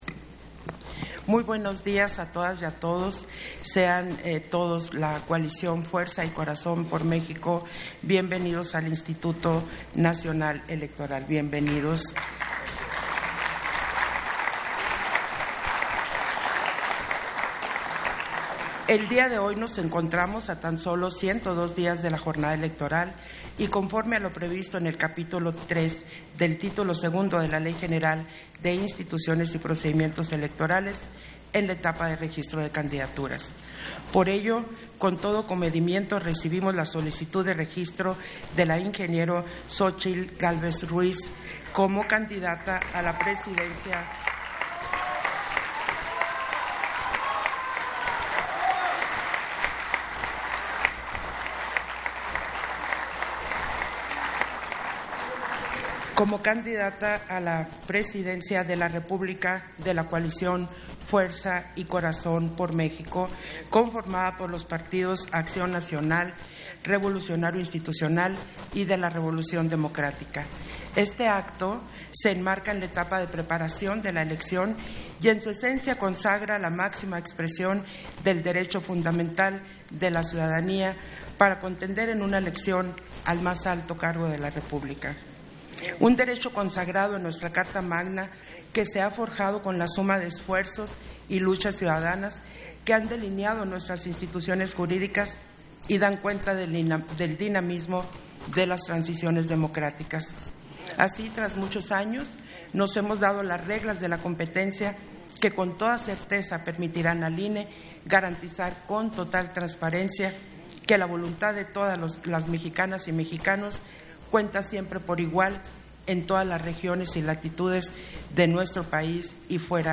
Intervención de Guadalupe Taddei, en le Registro de la Candidatura a la Presidencia de la República de la coalición, Fuerza y Corazón por México